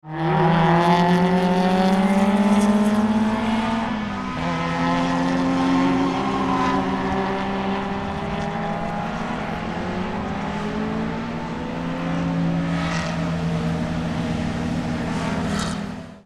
Fast-car-sound-effect.mp3